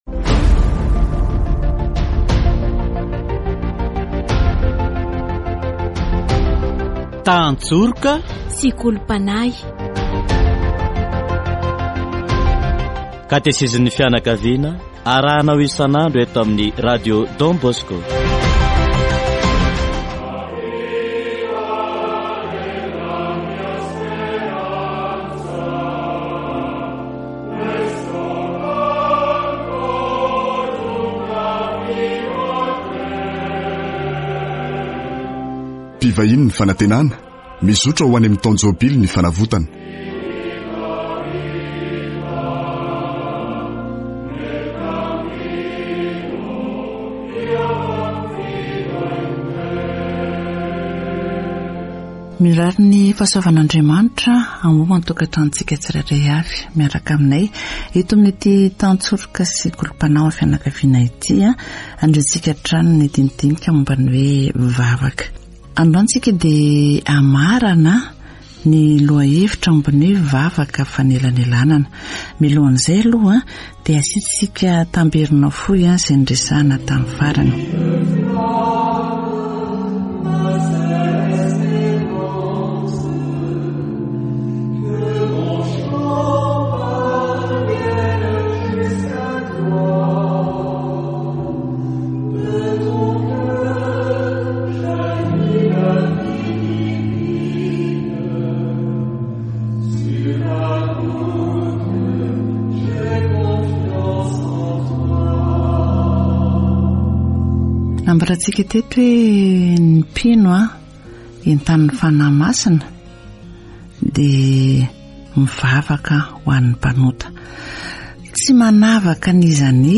Catégorie : Approfondissement de la foi
Catéchèse sur La prière d'intercession